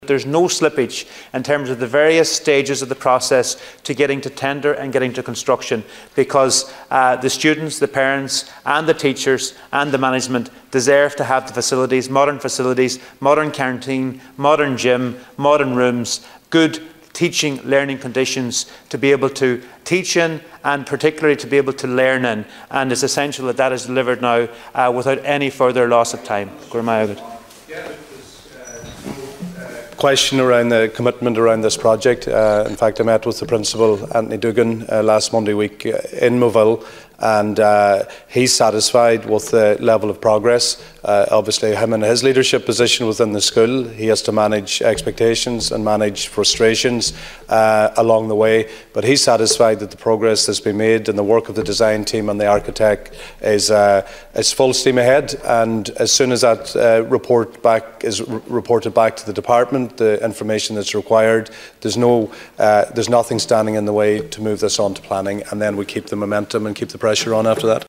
Minister Joe McHugh was pressed for an update by Donegal Deputy Charlie McConalogue in the Dail last evening.